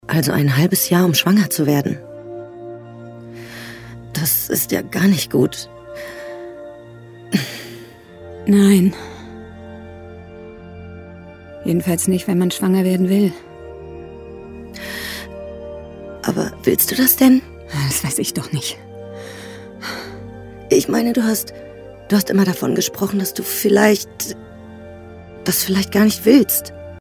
dunkel, sonor, souverän
Mittel plus (35-65)
Lip-Sync (Synchron)